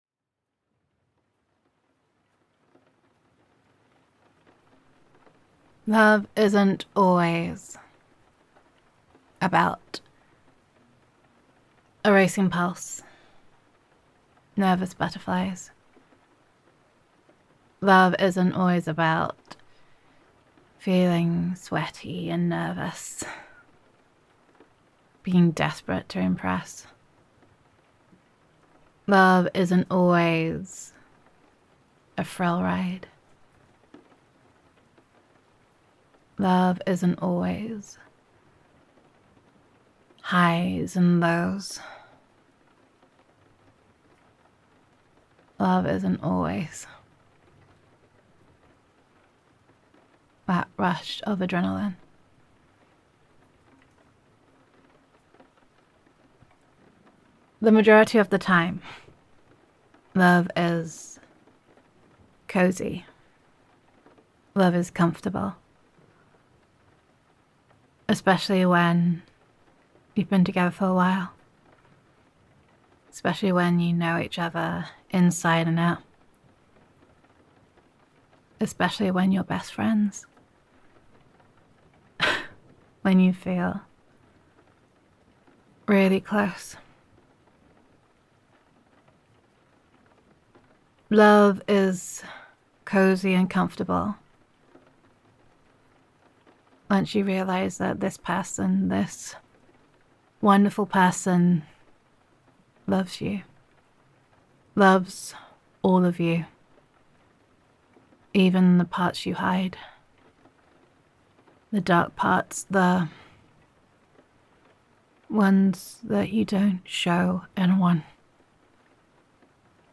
[F4A] Love Feel Like Home [Love Is Comfortable and Cosy][Adoration][Established Relationship][Warm Adoration][the Little Things][Gender Neutral][Love Is More Than Butterflies and Racing Heartbeats]